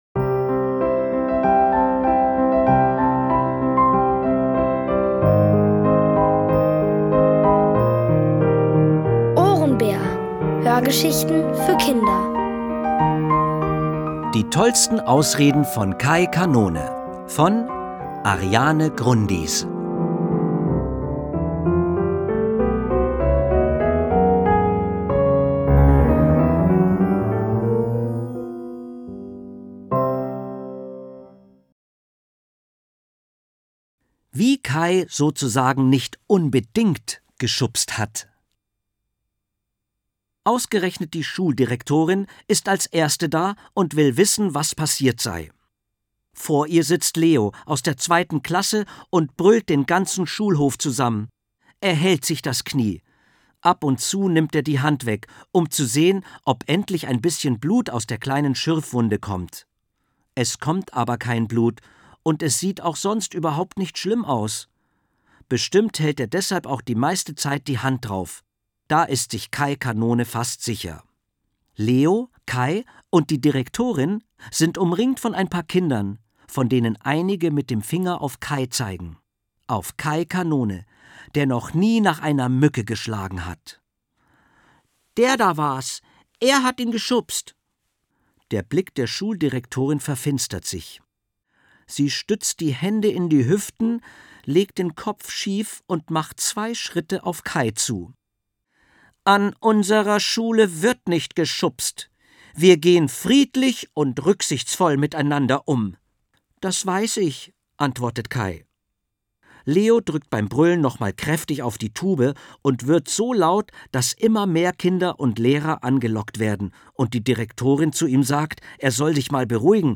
Von Autoren extra für die Reihe geschrieben und von bekannten Schauspielern gelesen.
Es liest: Dieter Landuris.